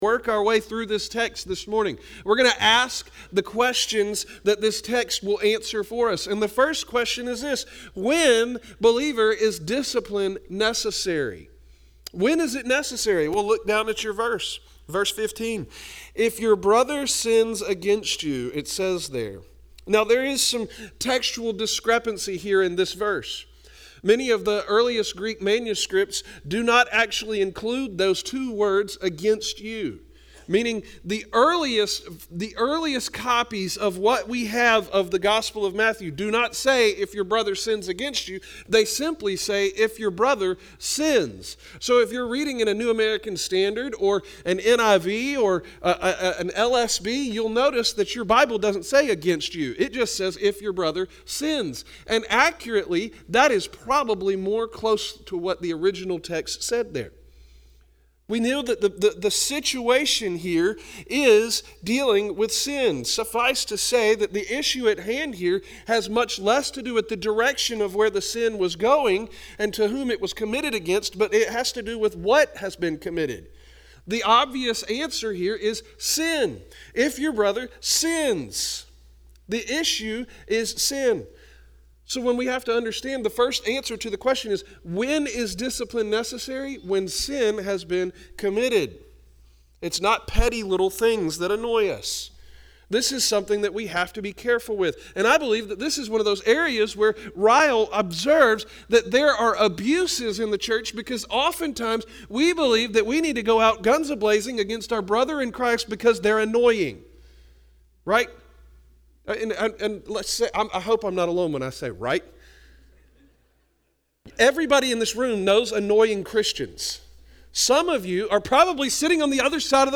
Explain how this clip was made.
After learning last week from Ephesians 5:11 about the need to "expose" the works of darkness, we took a break this week from Ephesians to study Matthew 18:15-20 to understand church discipline better. **The sermon recording starts about 3-4 minutes into the message.**